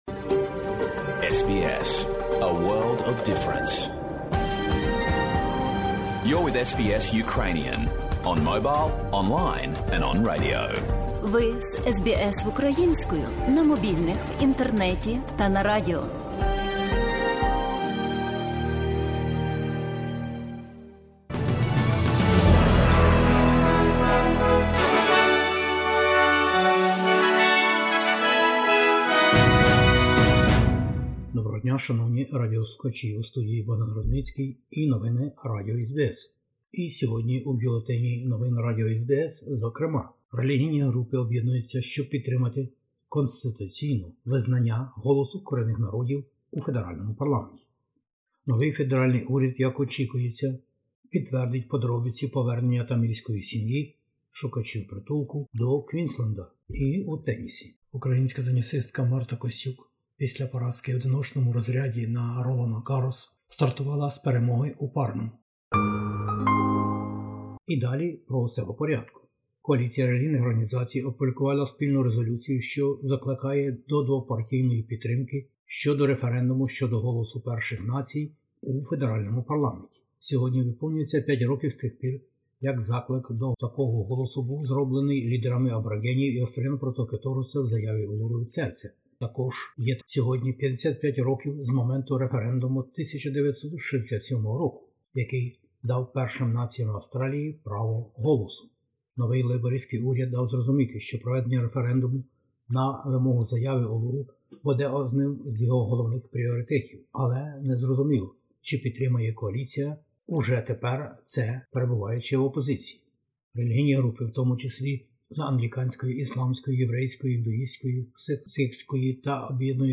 Бюлетень СБС новин українською мовою.